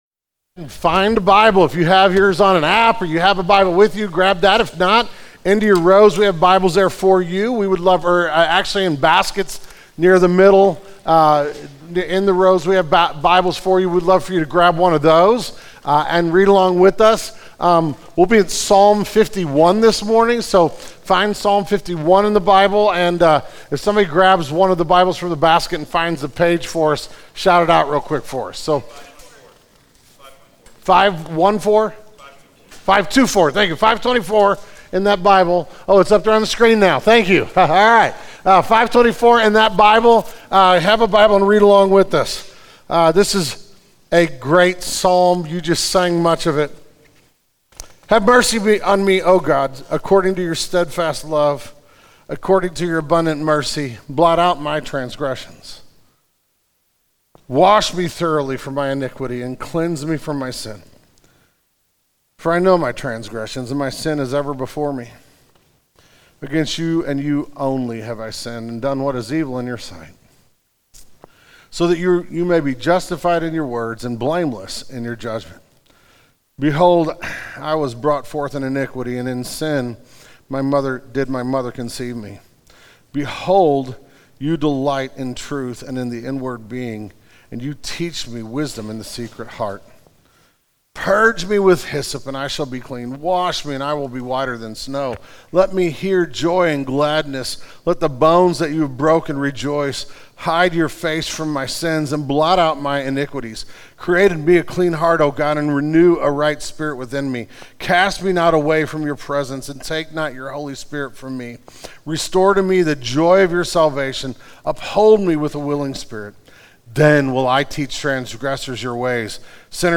The January 2021 Sermon Audio archive of Genesis Church.